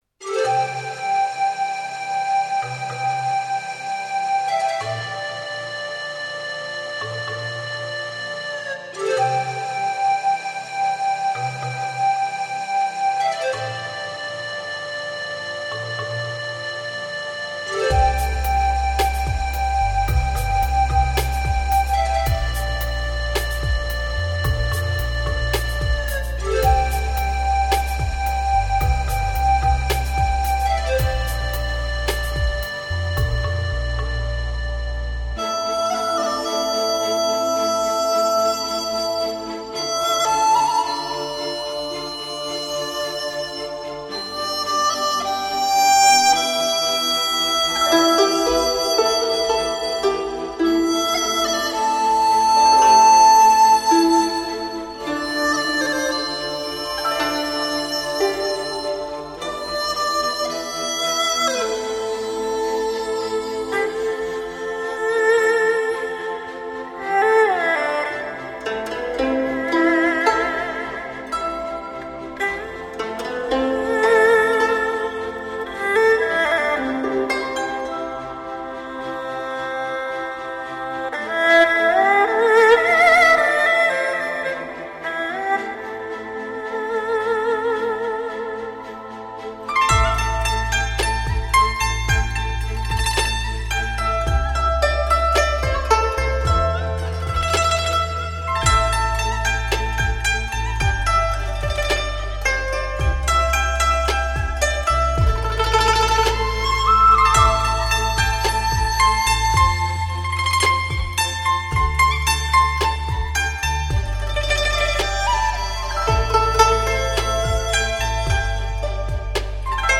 乐器演奏专辑
藏笛悠长 涤荡心灵 似幻还真